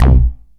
SYNTH BASS-1 0006.wav